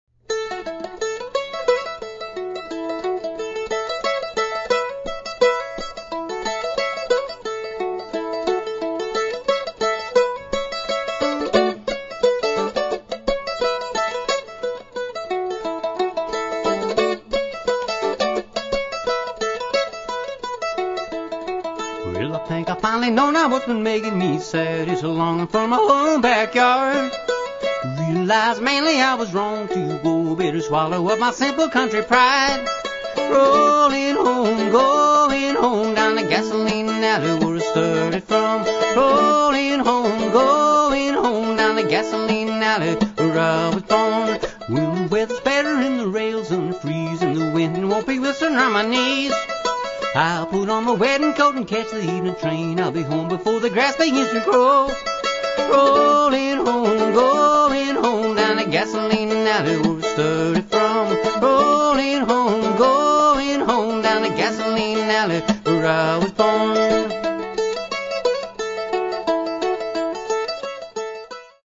almost takes on a traditional sound.
a definite old-time country feel to it